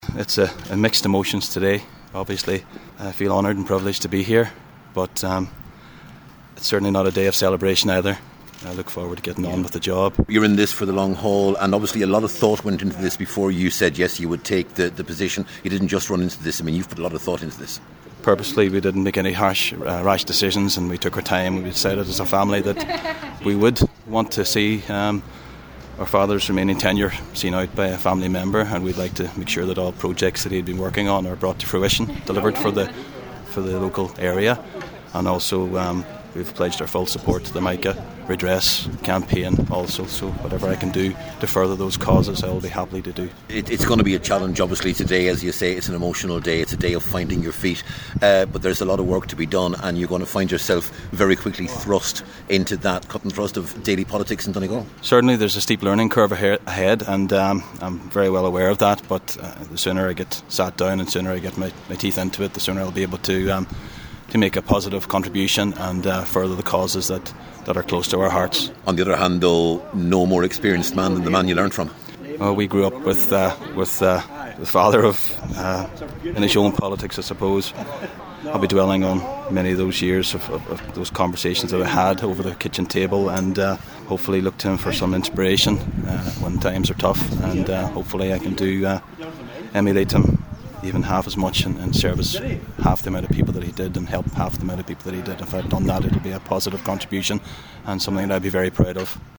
Newly elected Cllr McGuinness told the council he is honoured and humbled to replace his late father Bernard.